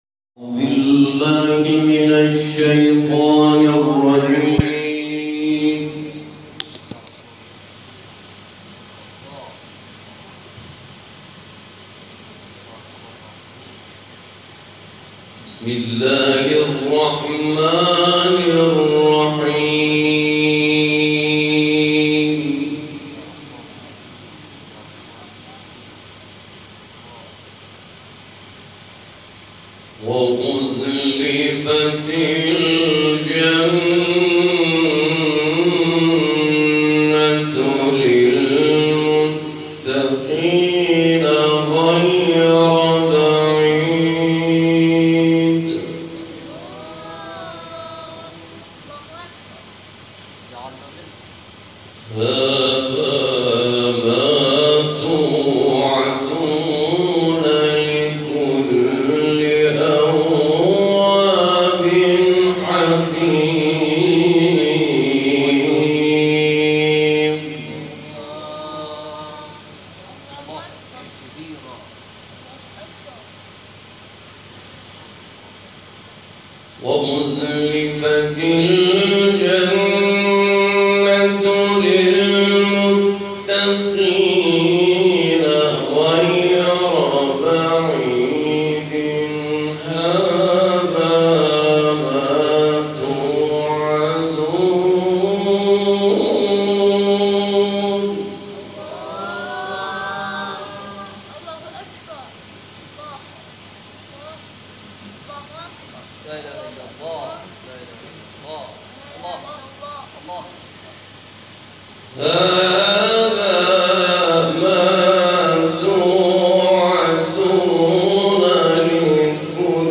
گروه فعالیت‌های قرآنی: محفل انس با قرآن کریم، روز گذشته، سیزدهم تیرماه در مسجد صاحب الزمان(عج) برگزار شد.